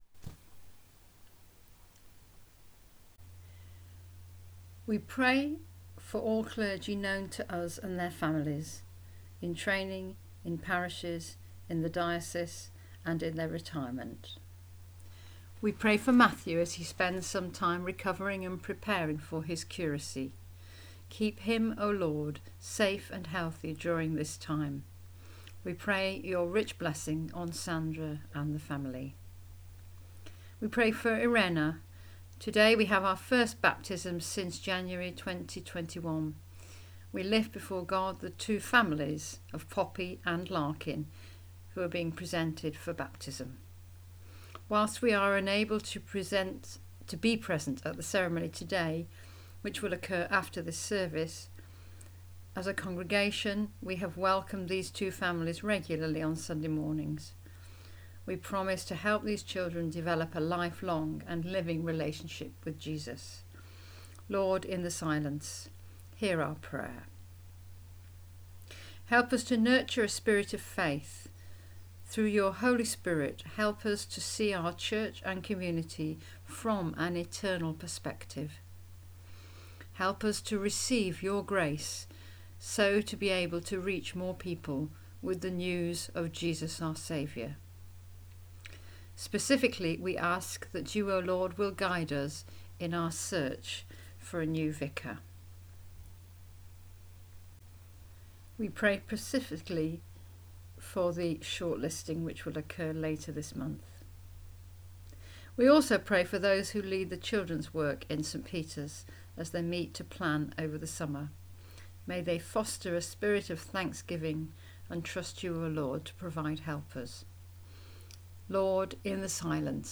Intercessions-Trinity-1.mp3